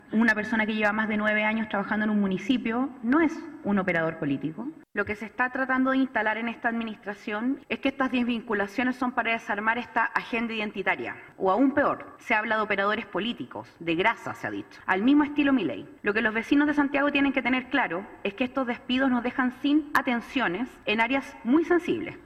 La concejala comunista, Camila Davagnino, aseguró que este no es el único caso y acusó que se despidió a gente de la Dirección de Seguridad, a las abogadas que llevaban causas judiciales por violencia de género y también a la gente de la sala multisensorial para niños con Trastorno del Espectro Autista.